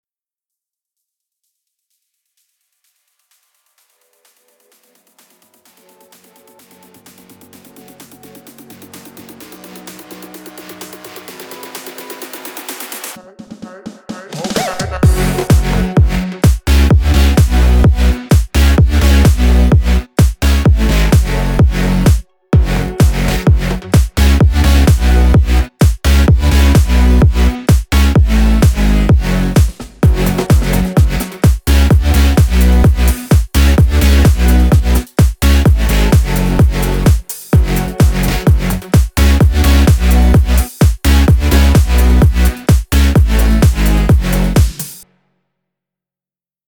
פלייבק לאיזה שיר היפ הופ
לאורך רוב הקטע רץ סימפול מהשיר המקורי
מכיוון שזה שיר בז’אנר דריל הייתי ממליץ לך להשתמש גם בסאונדים תואמי ז’אנר לדוג’ הקיק נשמע נשמע מידיי אלקטרוני סטייל EDM וכו’